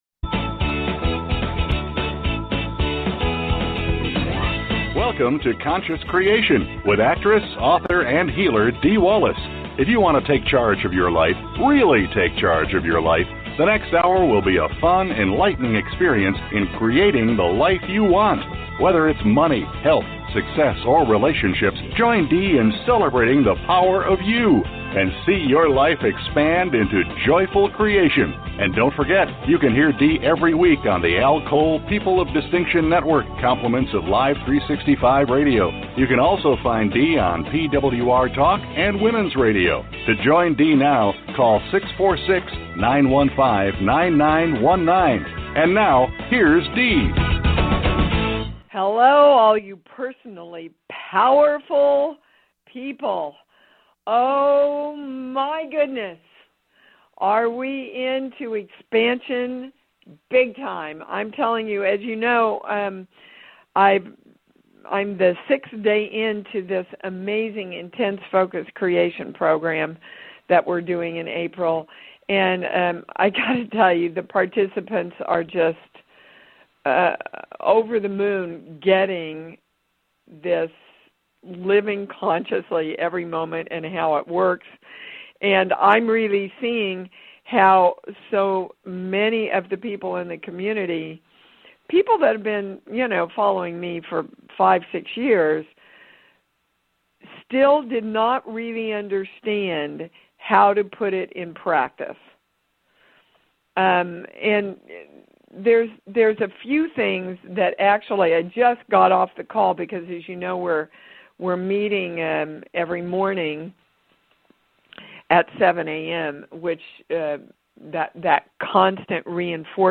Subscribe Talk Show Conscious Creation Show Host Dee Wallace Dee's show deals with the latest energy shifts and how they correspond with your individual blocks.